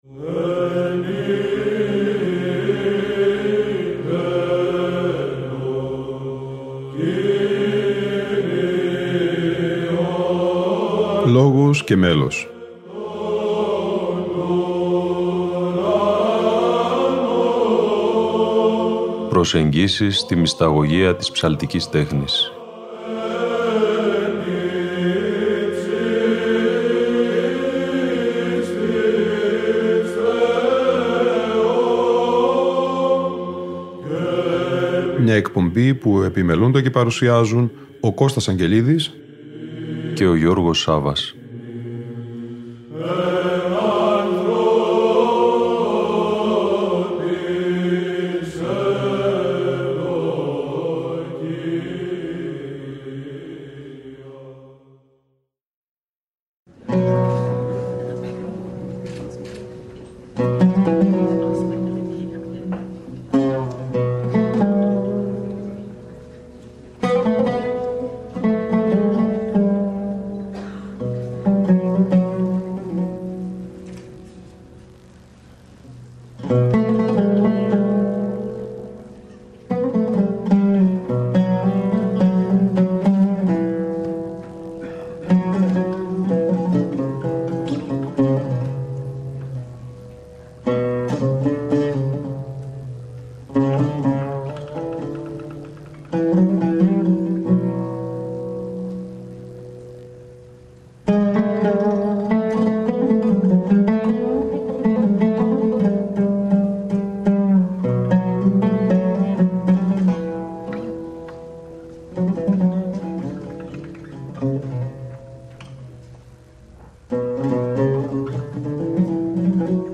Μία συνομιλία